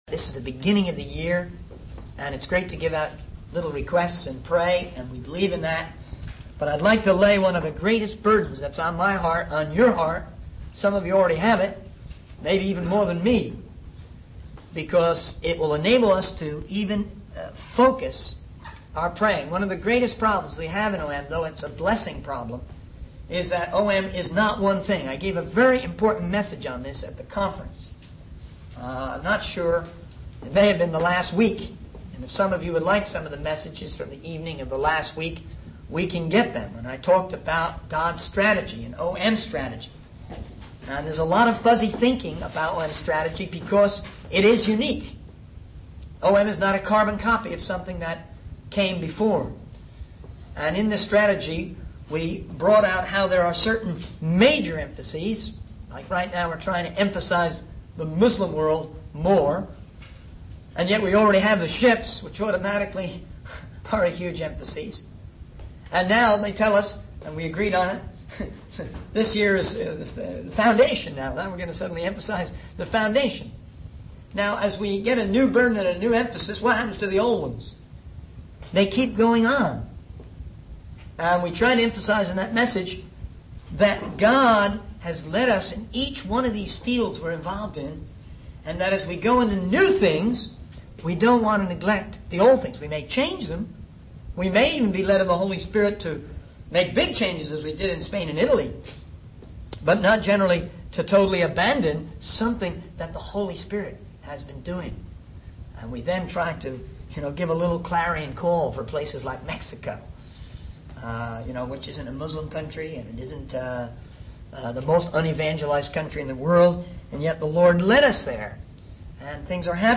In this sermon, the speaker highlights the importance of the audiovisual department in spreading the message of God.